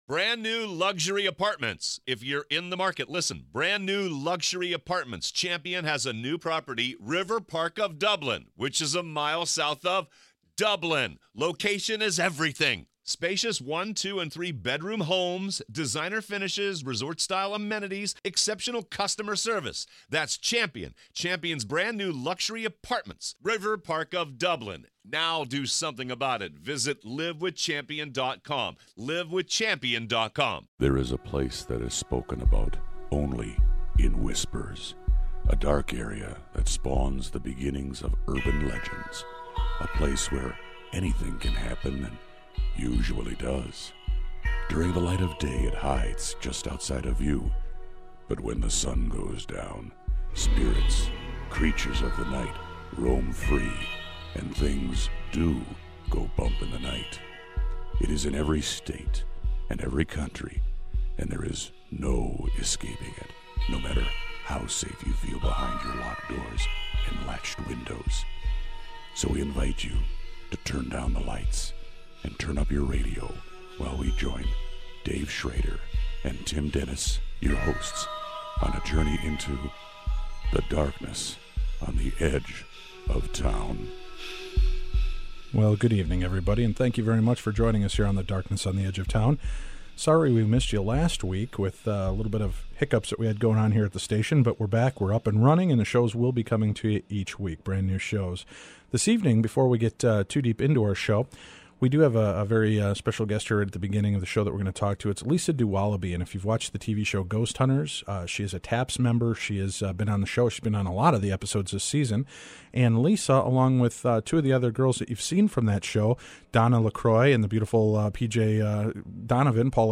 (Encore Presentation)